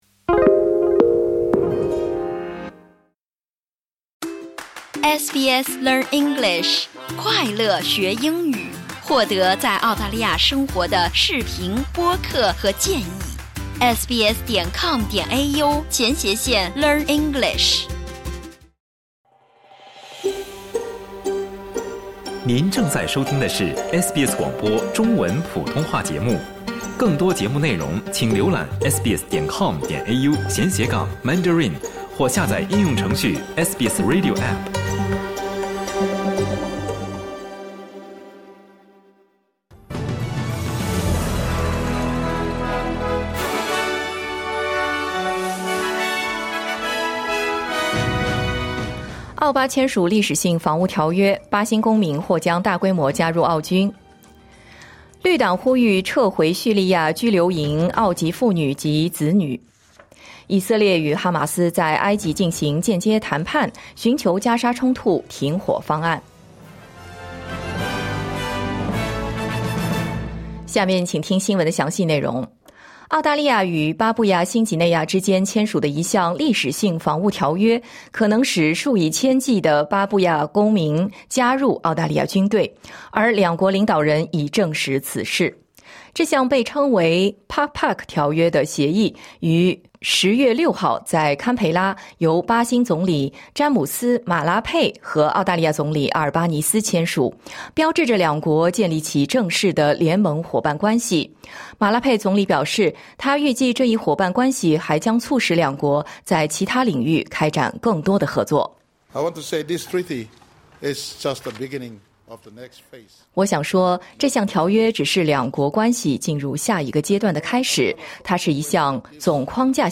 SBS早新闻 (2025年10月7日)